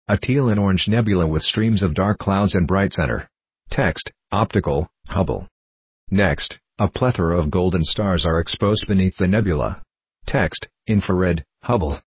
Audio Description.mp3